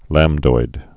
(lămdoid)